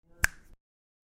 На этой странице собраны различные звуки щелчков пальцами – от четких и звонких до приглушенных и мягких.